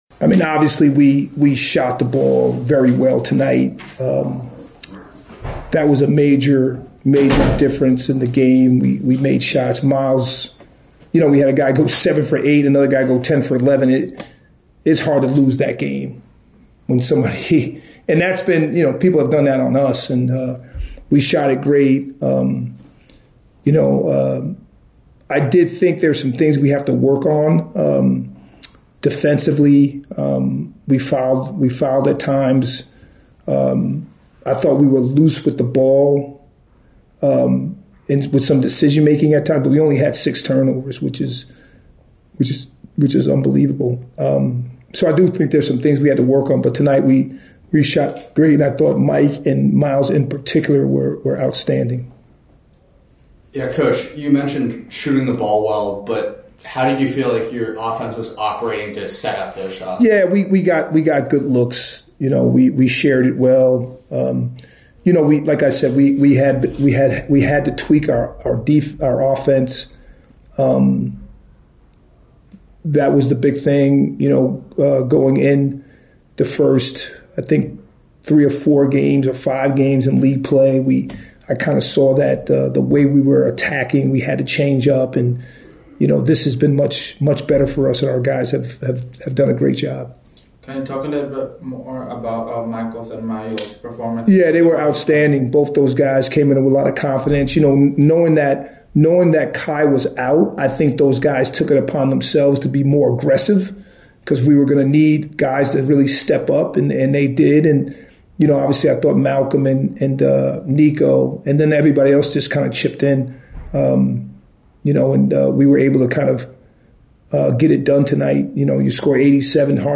Navy Postgame Interview